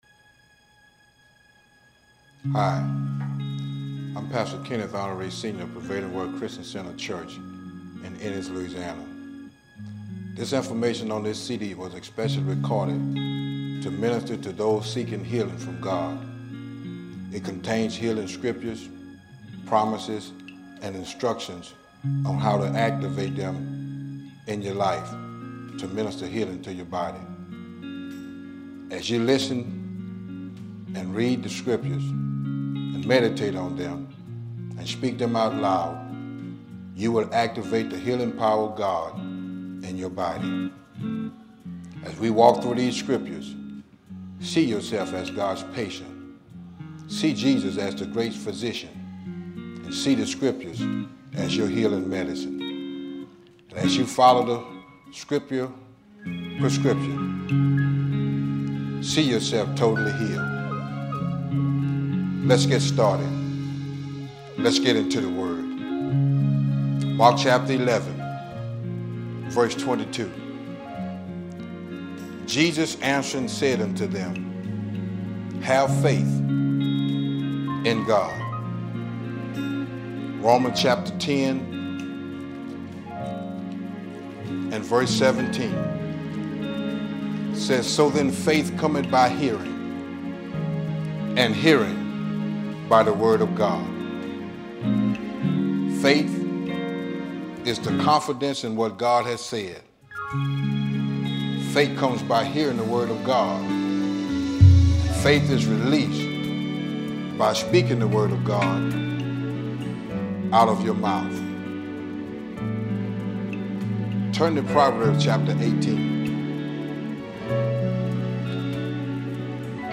Sermons - Prevailing Word Christian Center